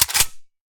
select-smg-3.ogg